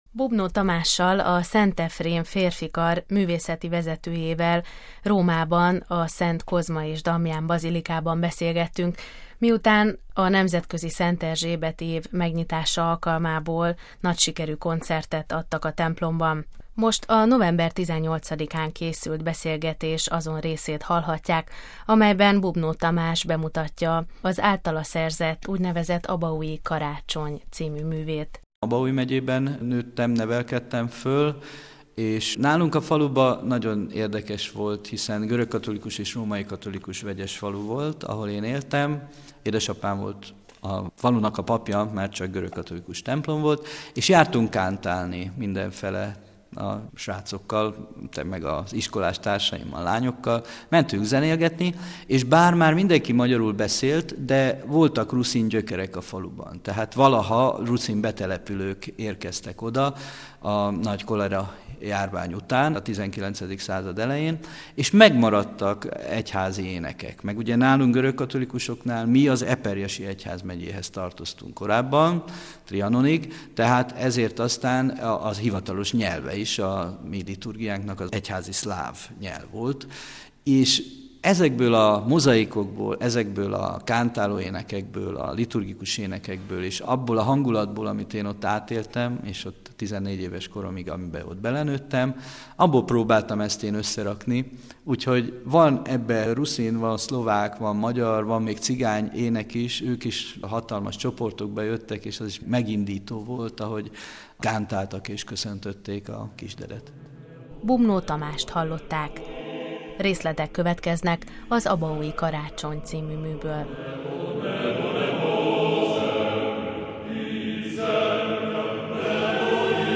majd részletek hangzanak el a műből.